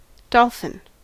Ääntäminen
US : IPA : [ˈdɑɫ.fɪn]